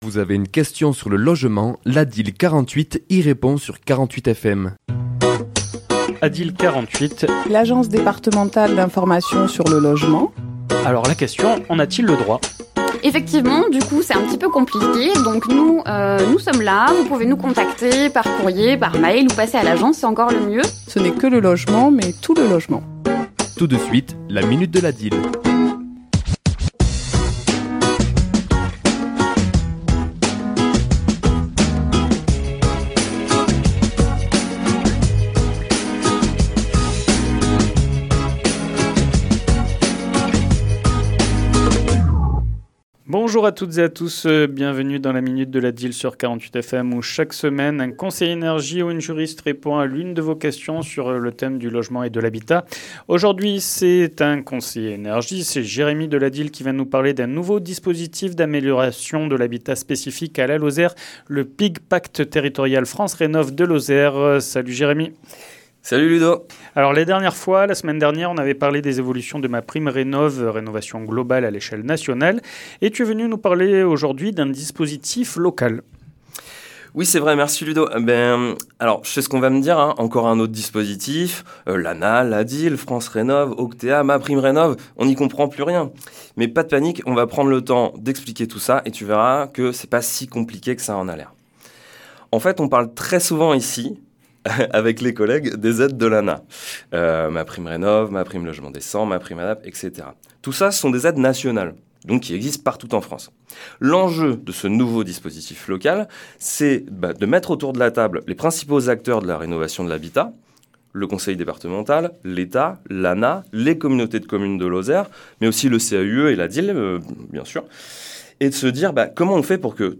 Chronique diffusée le mardi 30 septembre à 11h et 17h10